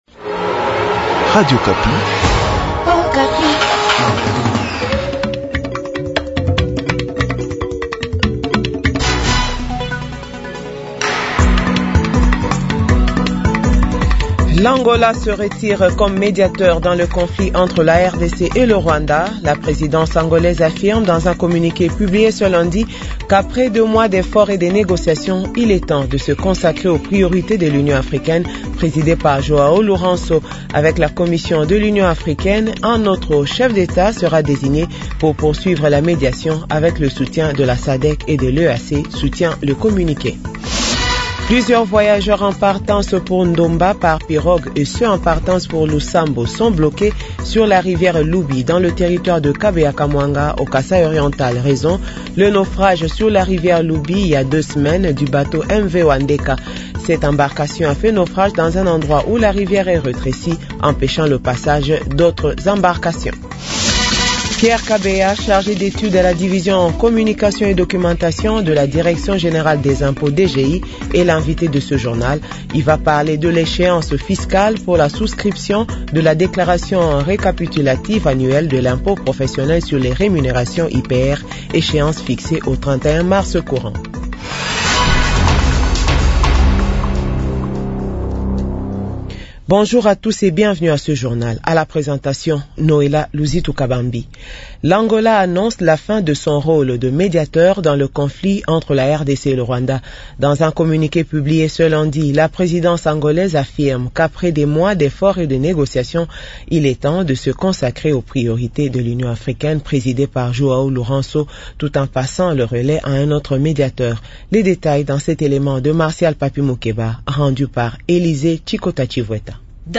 Journal 12h